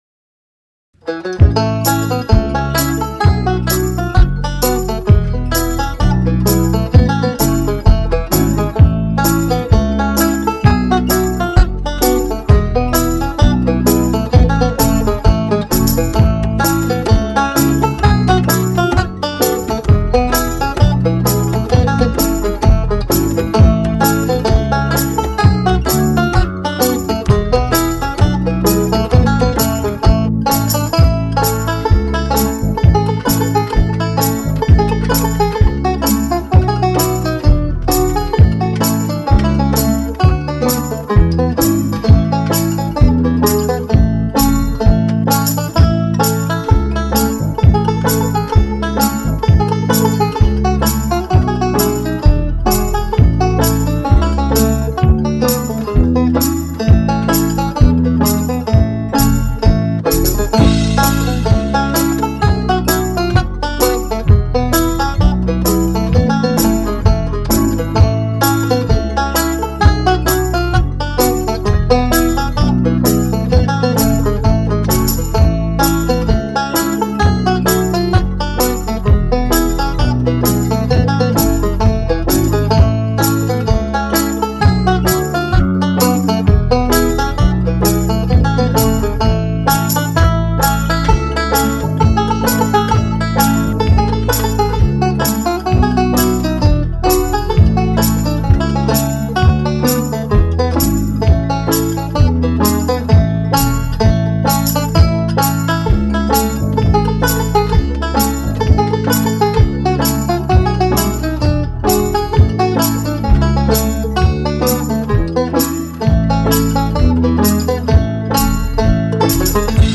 dunphy's_hornpipe.mp3